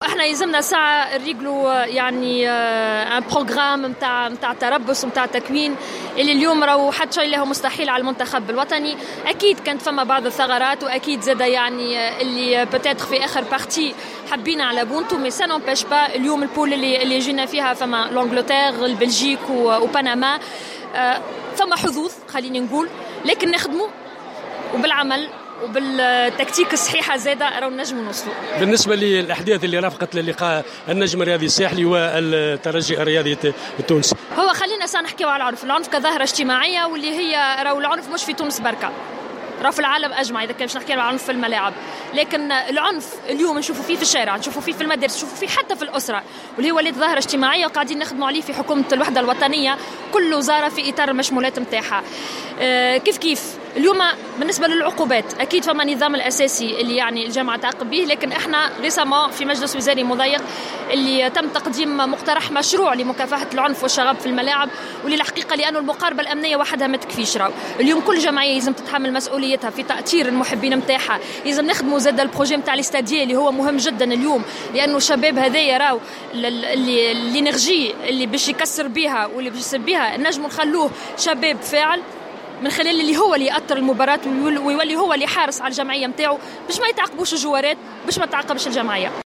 أكدت وزيرة شؤون الشباب والرياضة ماجدولين الشارني خلال إشرافها على تظاهرة نساء الرياضة بالقاعة المغطاة بالكاف أن المنتخب الوطني قادر على تحقيق نتائج إيجابية خلال مشاركته في مونديال روسيا 2018 شريطة التحضير الجيد لهذا الموعد وانه لا وجود لأمر مستحيل في كرة القدم.